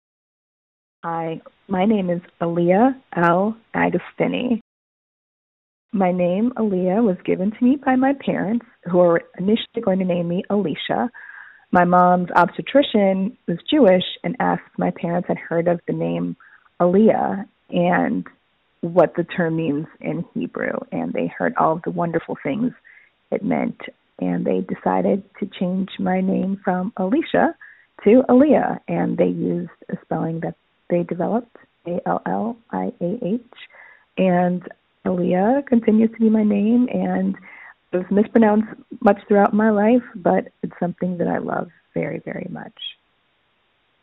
Audio Name Pronunciation